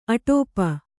♪ aṭōpa